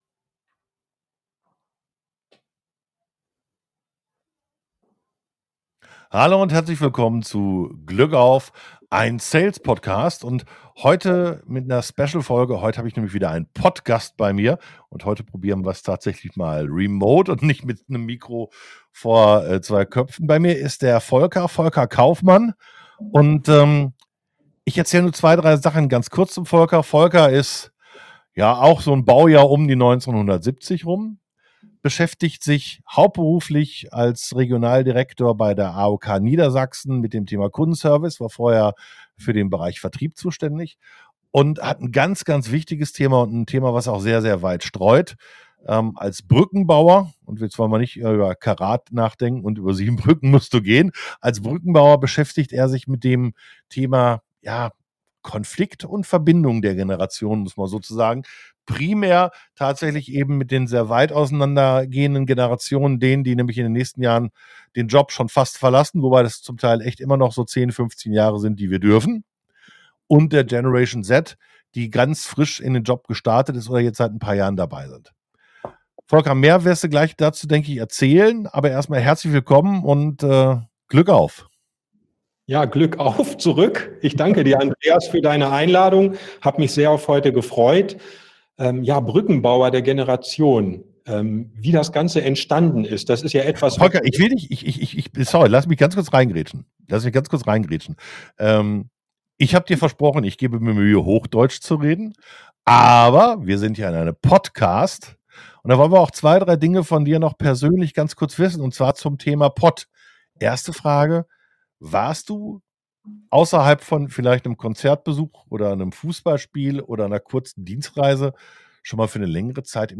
Es war ein kurzweiliges Gespräch und wir hoffen, ihr habt auch eine gute Unterhaltung beim Hören Viel Spaß bei der Folge und Glück auf!